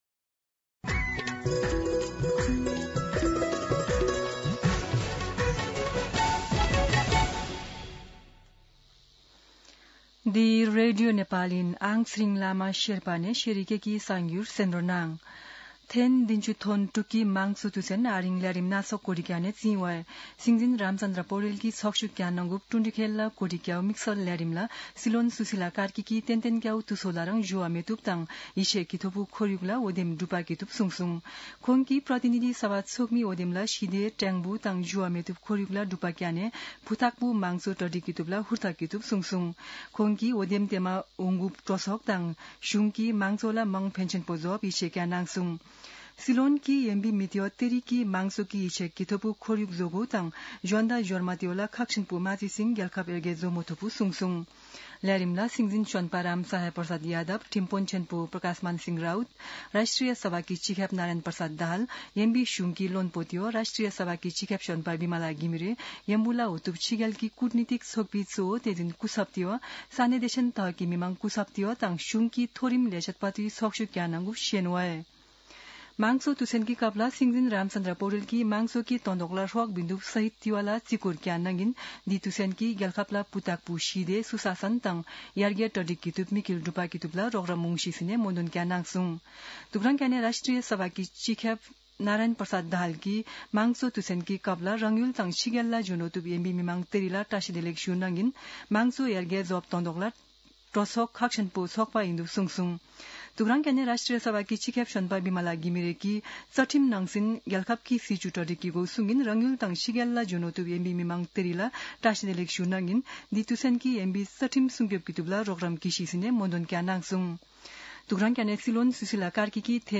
शेर्पा भाषाको समाचार : ७ फागुन , २०८२
sherpa-news.mp3